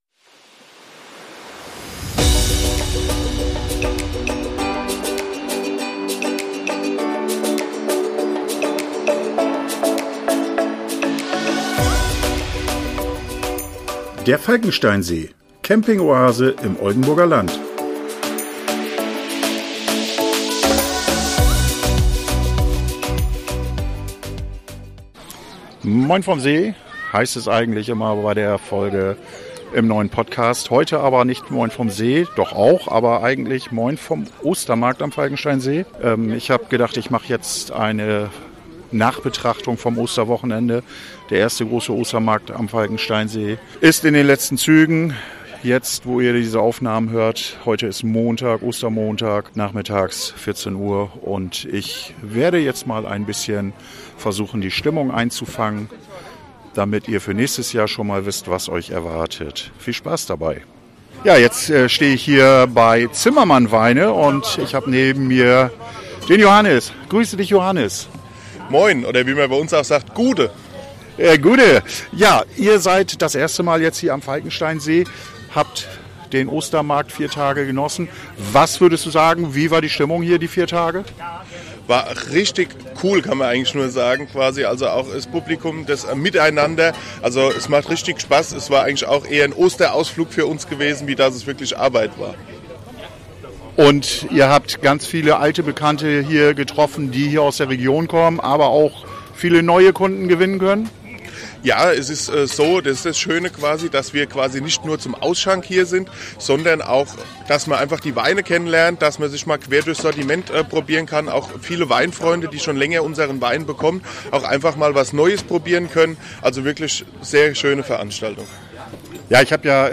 Ich habe mich am Ostermarkt unter das Volk gemischt und ein wenig die Stimmung eingefangen.